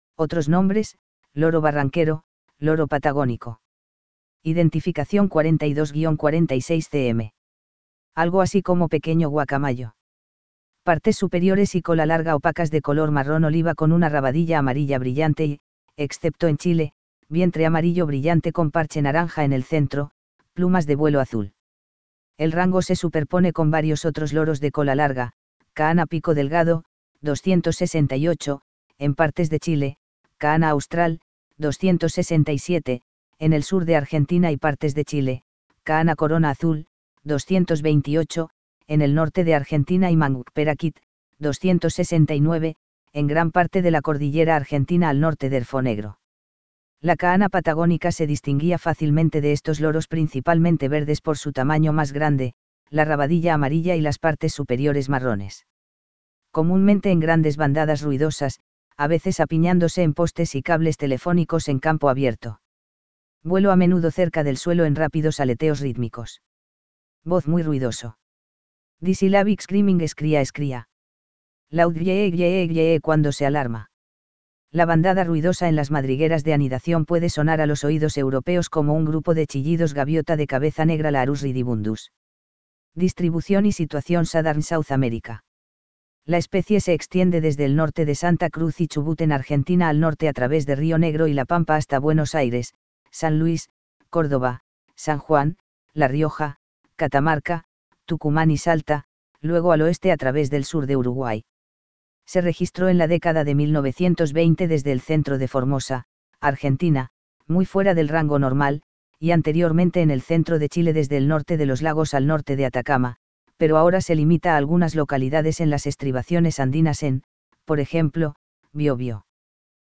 Posee una voz fuerte como otros de la familia pero es ronca y grave, bien característica.
VOZ Muy ruidoso. Disyllabic screaming scree-ah scree-ah. Loud gyeee gyeee gyeee cuando se alarma.
Lorobarranquero.mp3